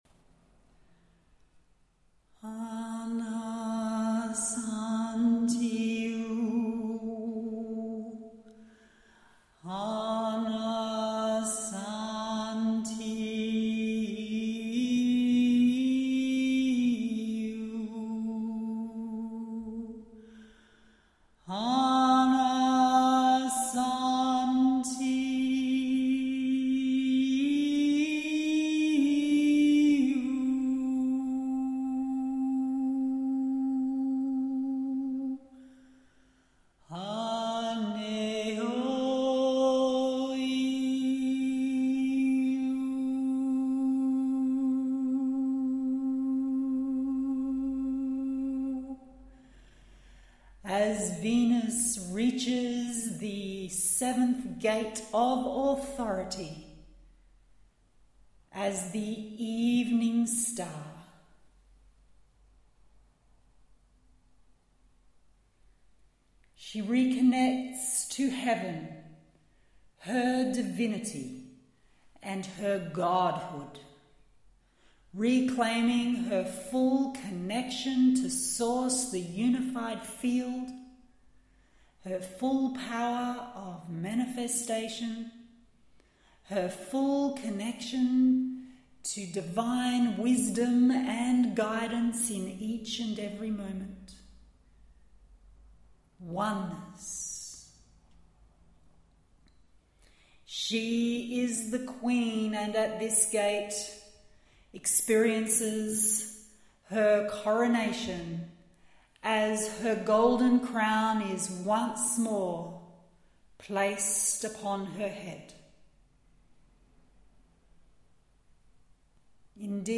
SNEAK PEEK of this AMAZING MEDITATION/TRANSMISSION – sharing the first 2 minutes of the 11 minute Celestial Resonance Meditation Journey, you will need to purchase the mp3 audio file to experience the full journey.